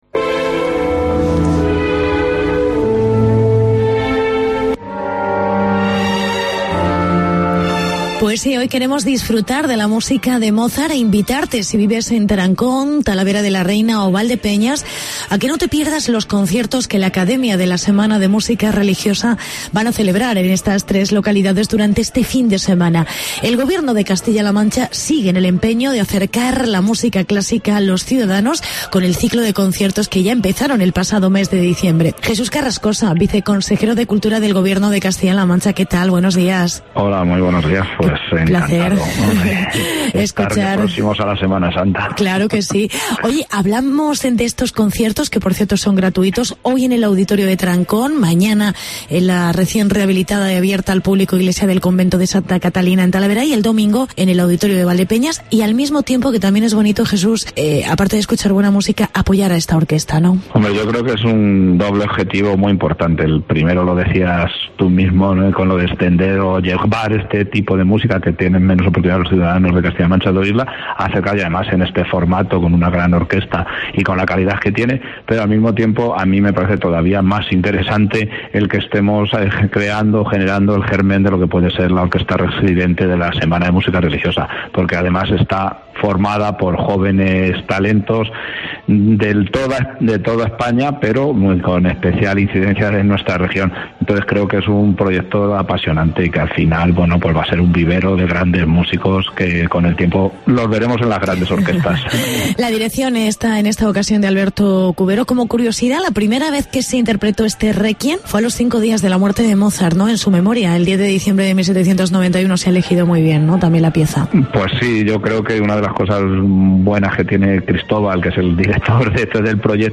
Entrevista con el Viceconsejero de Cultura: Jesús Carrascosa